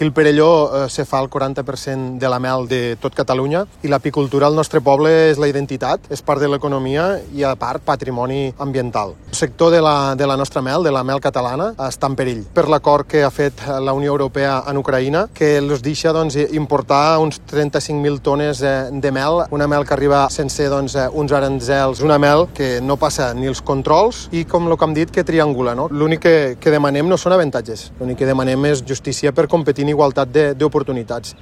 Samuel Ferré, alcalde del Perelló